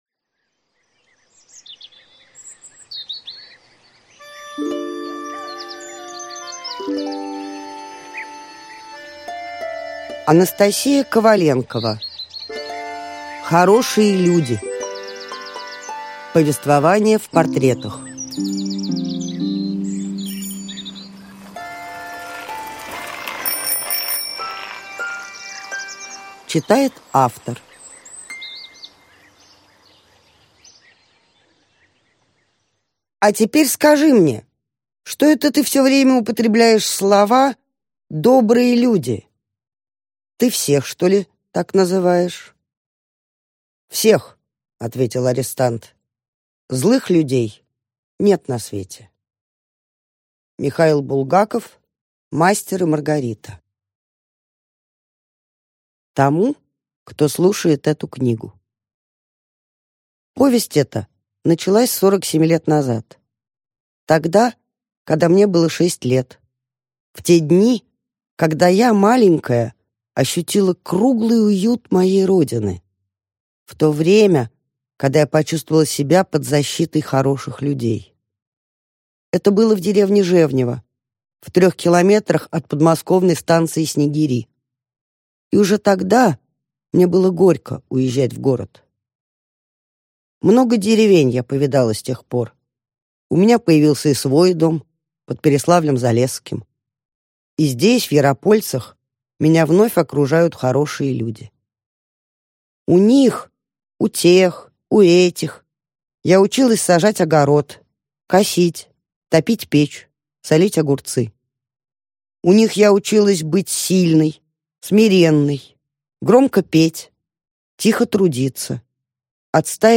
Аудиокнига Хорошие люди. Повествование в портретах | Библиотека аудиокниг